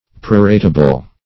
Search Result for " proratable" : The Collaborative International Dictionary of English v.0.48: Proratable \Pro*rat"a*ble\, a. Capable of being prorated, or divided proportionately.